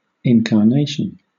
wymowa:
IPA[ˌɪnkɑːˈneɪʃən]